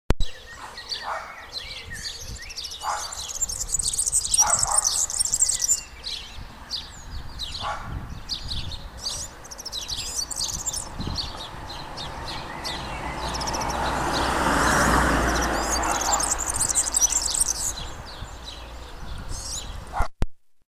Verzellino Serinus serinus (Linnaeus, 1766)
Canta spesso in volo quando esegue la parata con un caratteristico battito d'ali.
Ascolta il canto del Verzellino (in sottofondo dei Passeri d'Italia)